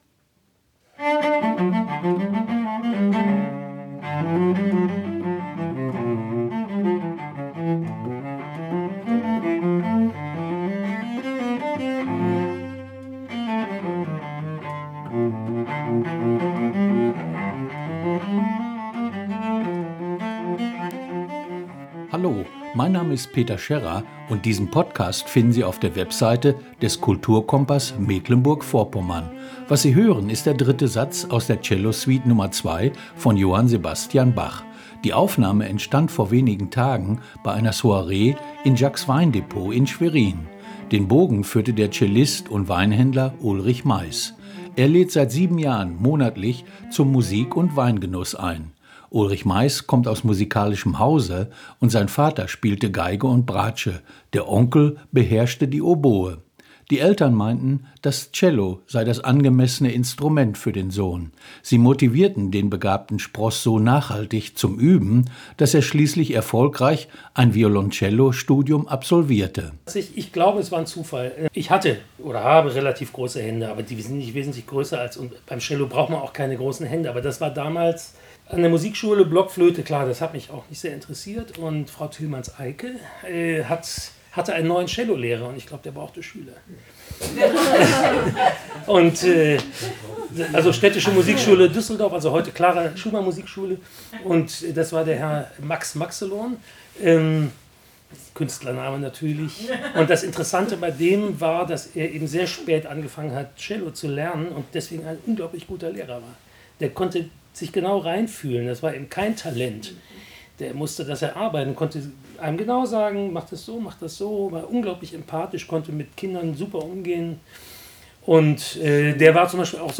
Cellectric – Cello klassich und elektrisch!